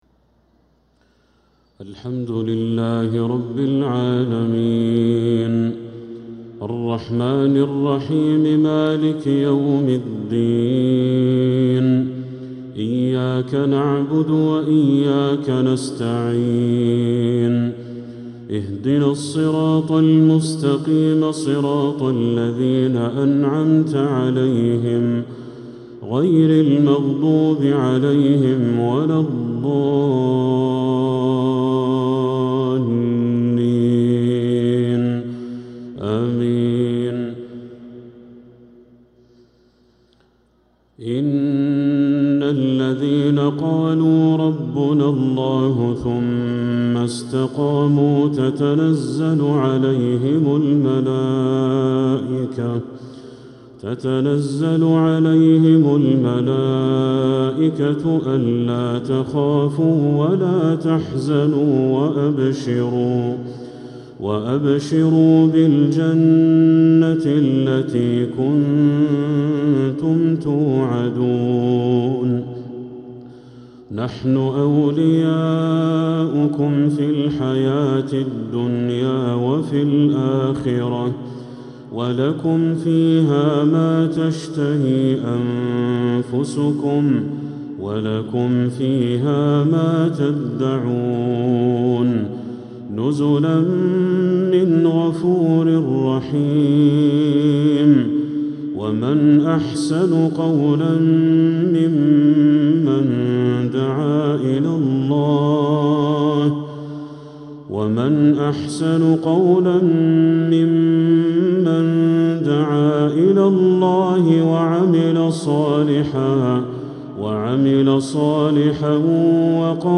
عشاء الجمعة 3-7-1446هـ من سورتي فصلت 30-36 و المنافقون 9-11 | Isha prayer Surah Fussilat and al-Munafiqun 3-1-2025 🎙 > 1446 🕋 > الفروض - تلاوات الحرمين